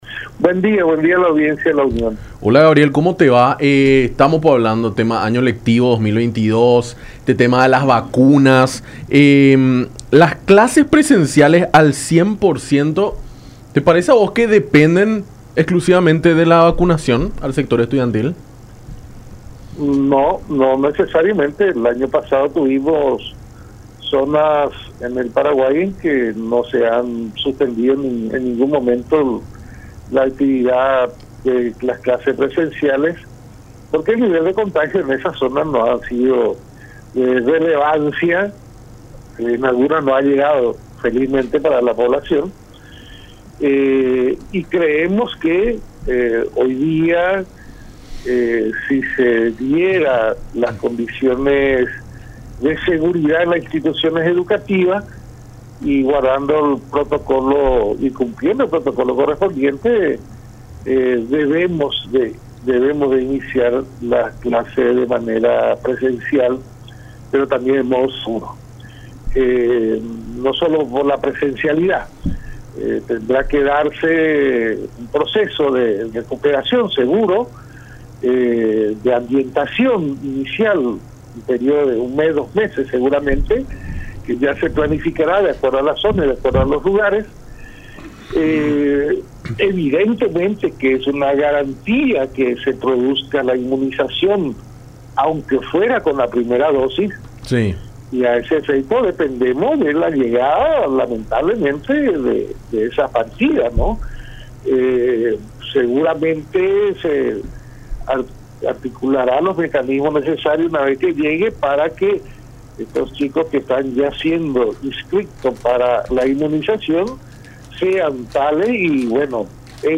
en conversación con Nuestra Mañana por La Unión